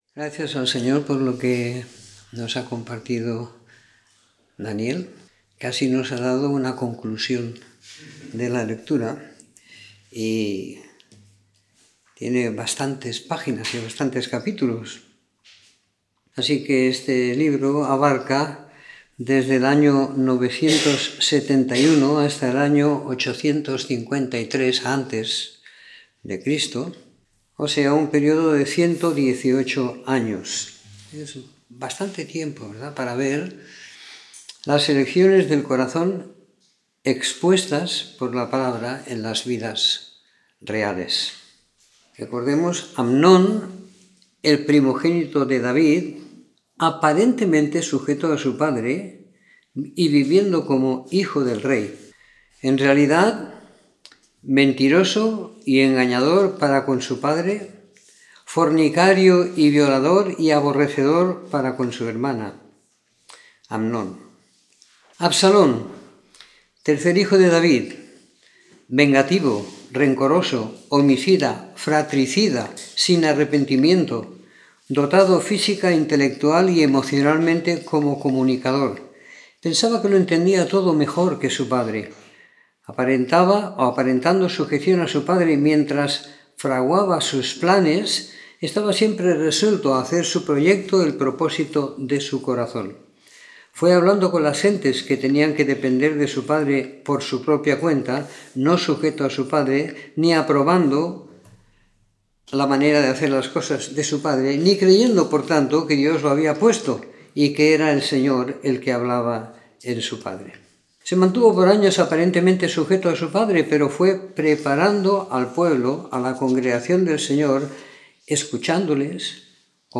Comentario en el primer libro de Reyes siguiendo la lectura programada para cada semana del año que tenemos en la congregación en Sant Pere de Ribes.